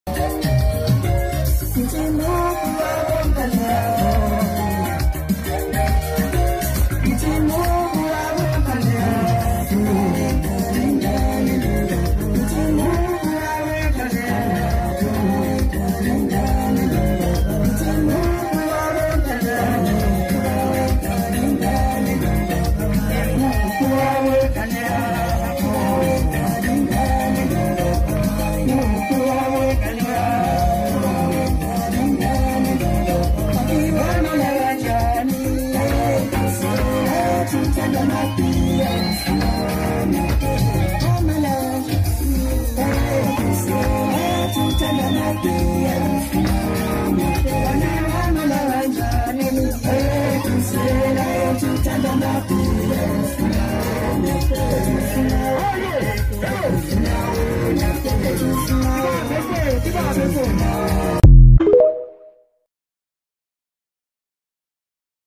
Short Song Snippet